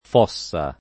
fossa [ f 0SS a ] s. f.